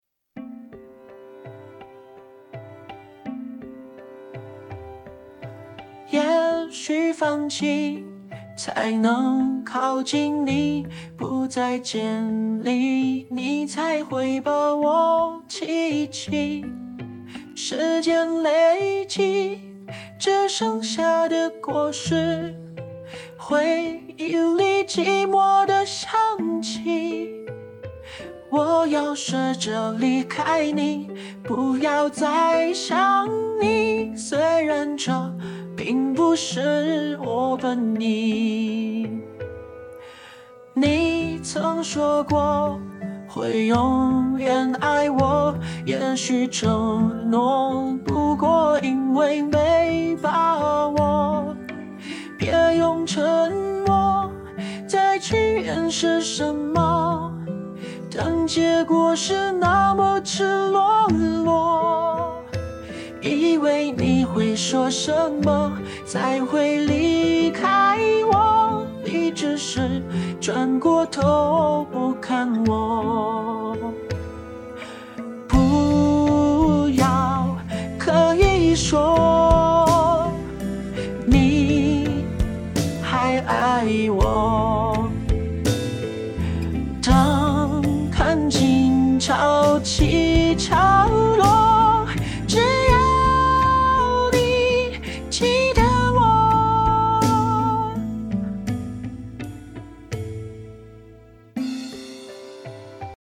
RVC模型 《蜡笔小新》阿呆角色RVC模型